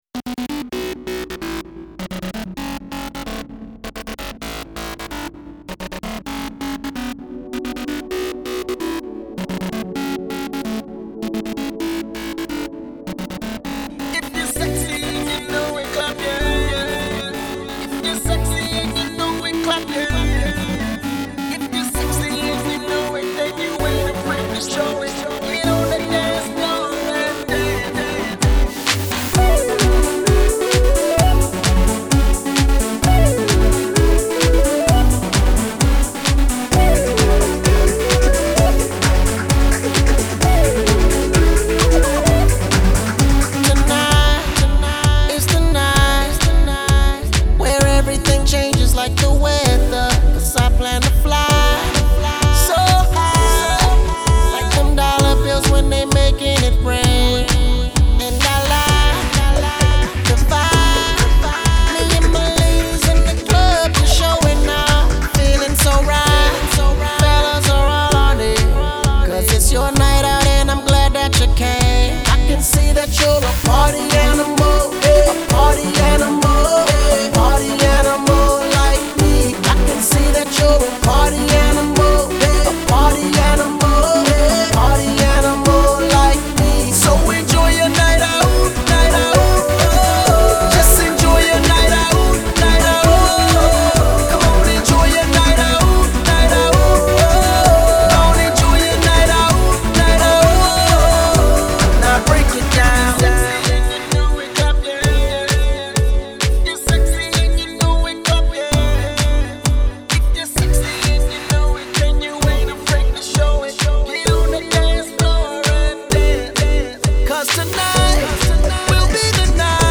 Hip Hop Music